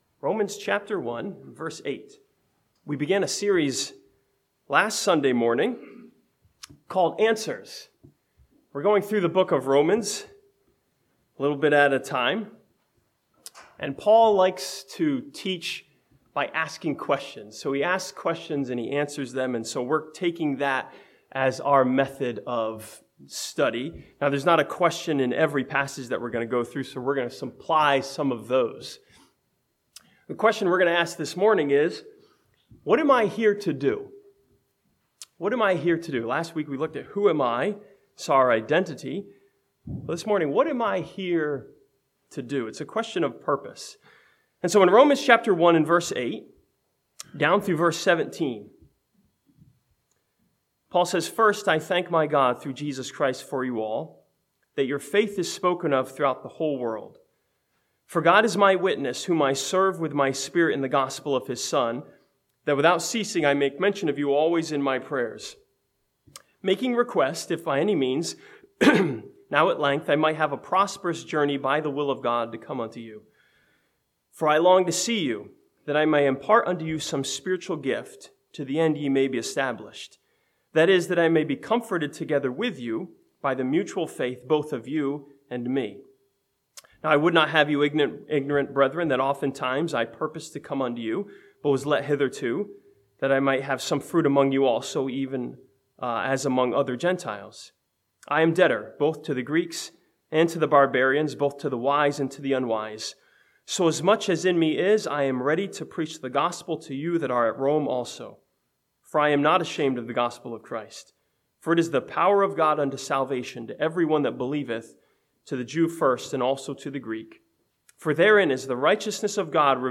This sermon from Romans chapter 1 ask the question: what am i here to do? and finds the answer in Paul's purpose.